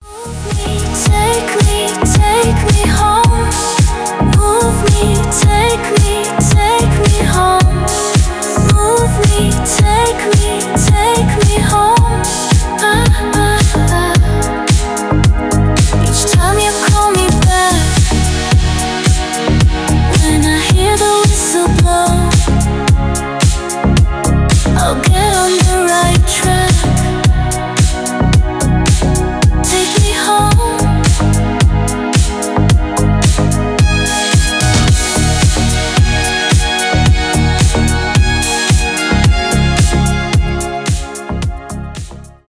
красивый женский голос
deep house